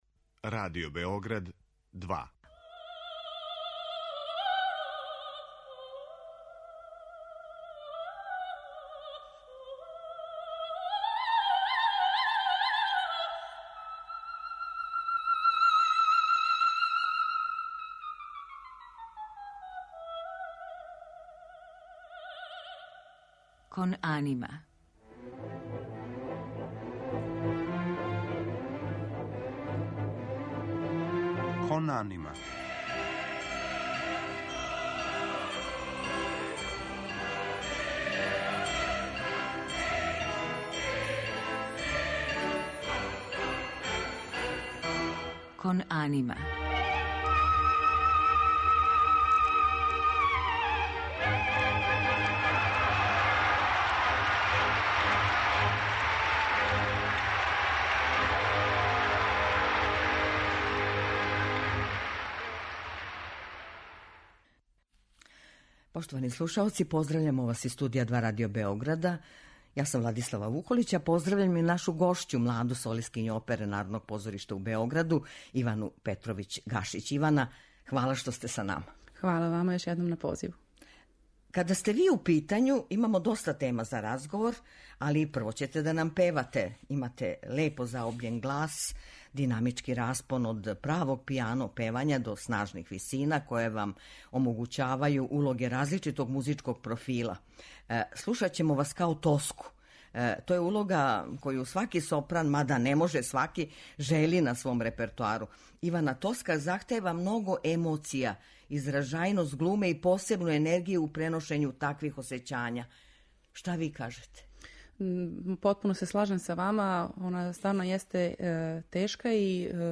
Представиће се као тумач сопранског репертоара кроз арије из опера Ђакома Пучинија и Ђузепа Вердија.